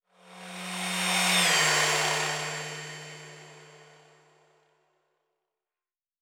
Fly By 02_5.wav